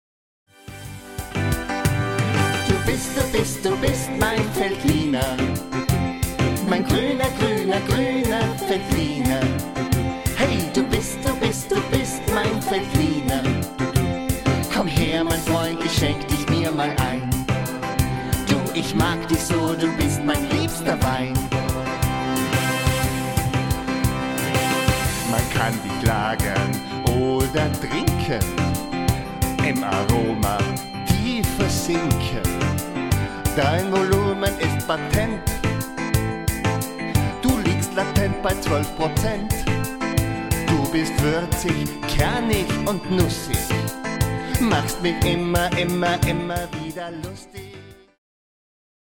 Cooking Fever Sound.